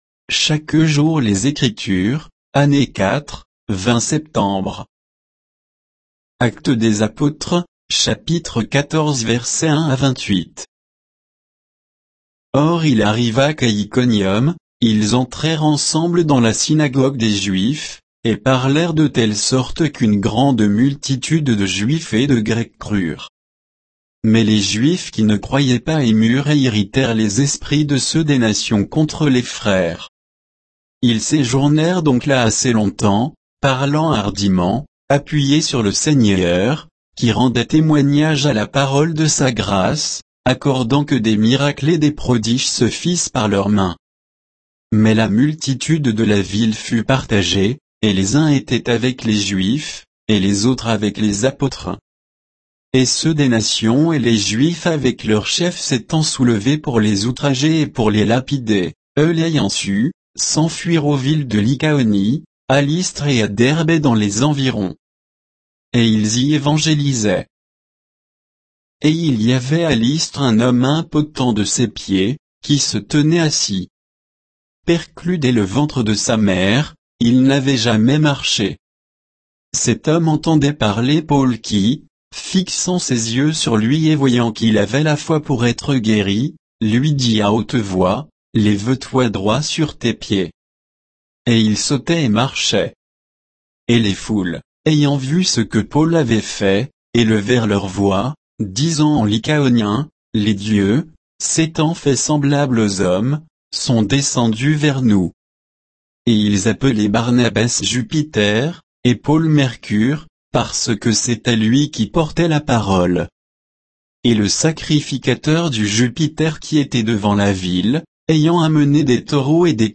Méditation quoditienne de Chaque jour les Écritures sur Actes 14, 1 à 28